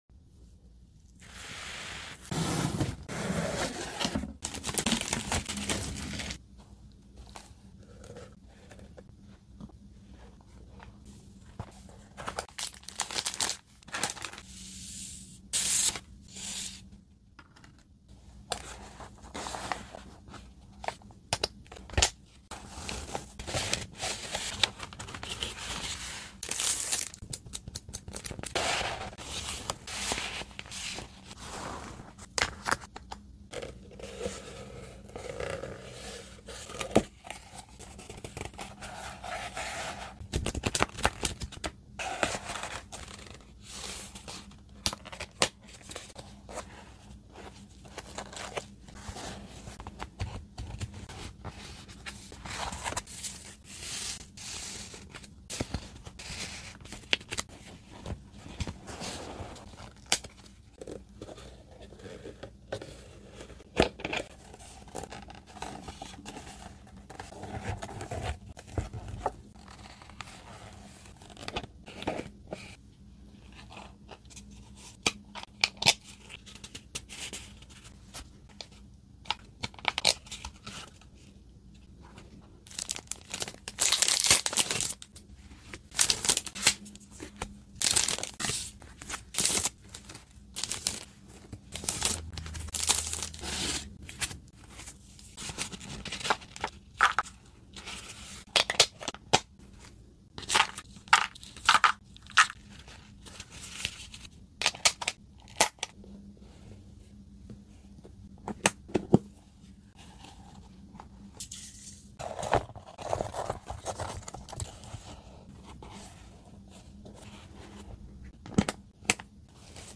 ASMR Unbox & Organize With sound effects free download